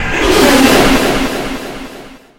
Animals Return Jumpscare Sound